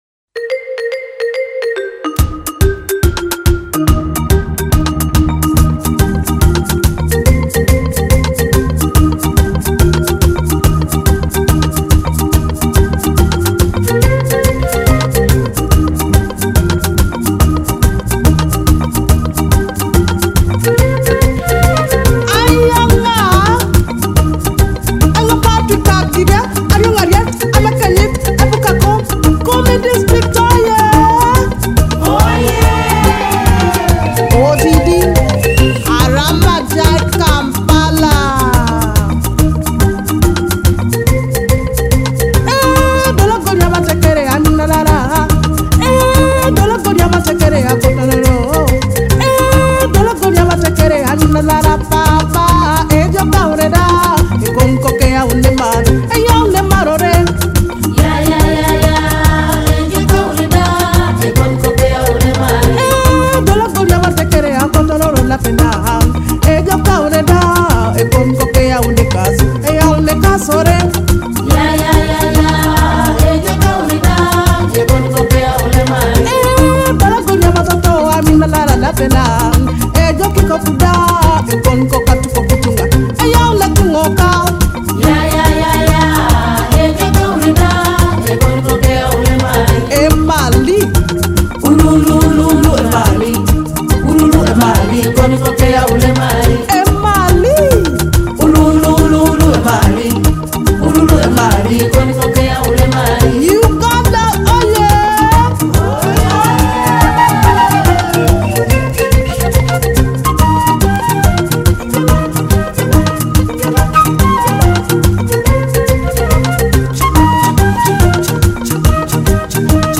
Experience the infectious, uplifting melodies of